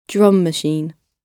Drum_machine.wav